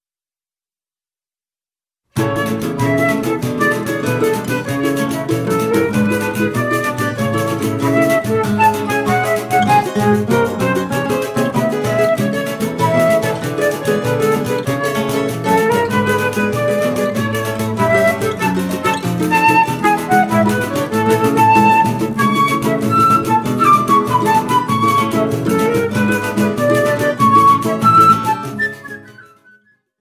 flauta